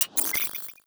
Robotic Game Notification 9.wav